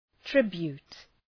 Προφορά
{‘trıbju:t}